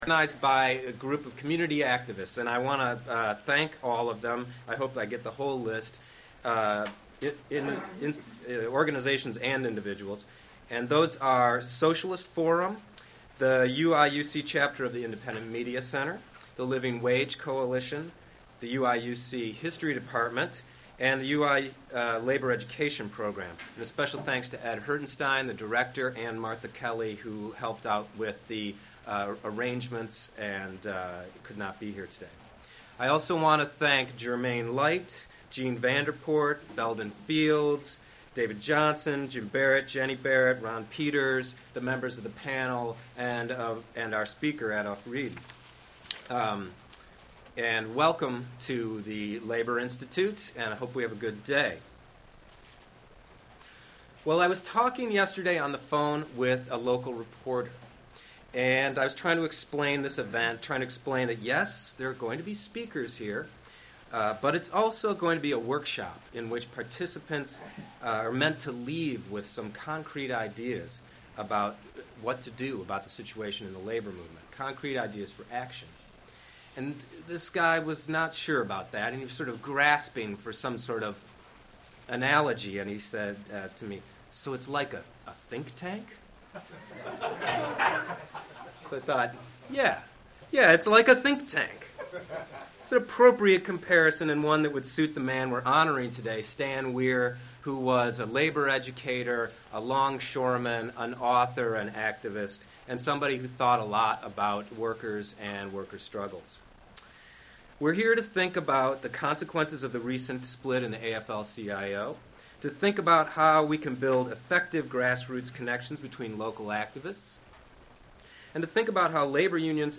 The keynote address was given by Adolph Reed, Jr. who writes for the Progressive and The Nation magazines.